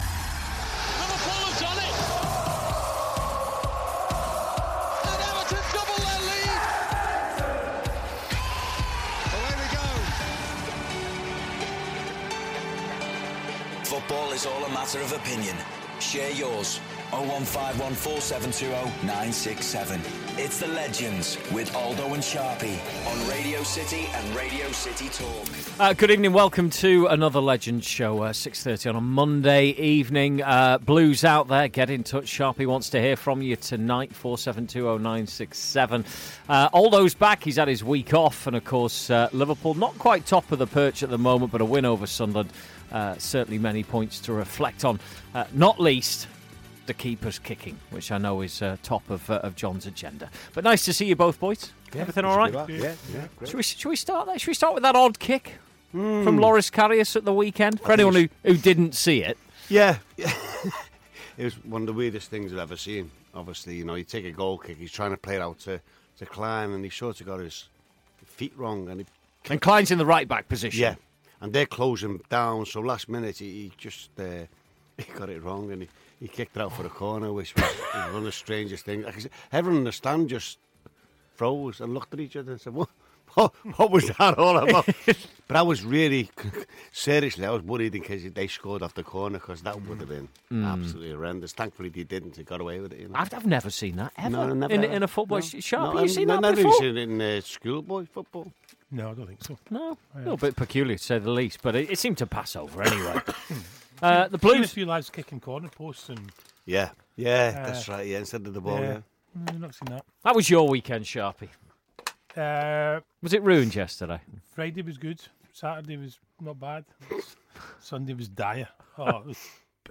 Graeme Sharp and John Aldridge to take your calls and review the weekend's games.